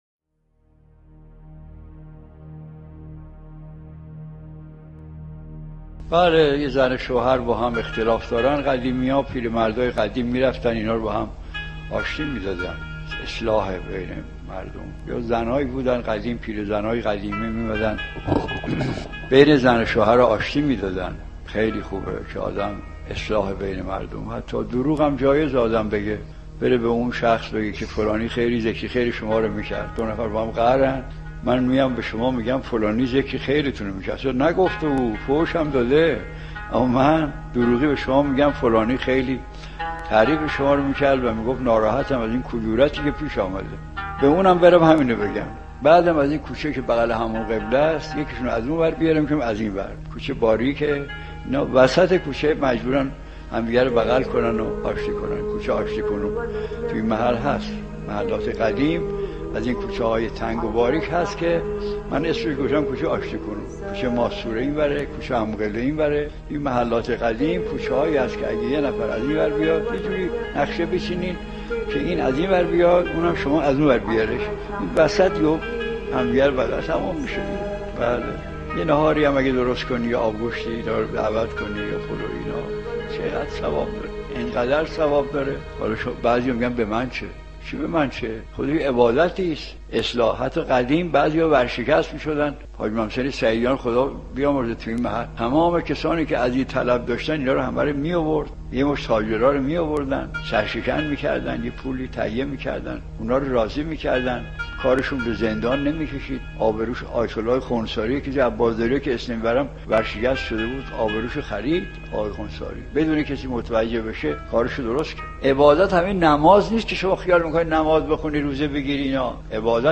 دانلود بین مردم را اصلاح کنید سخنران : آیت الله مجتهدی تهرانی حجم فایل : 3 مگابایت زمان : 3 دقیقه توضیحات : موضوعات : دسته بندی ها آیت الله مجتهدی تهرانی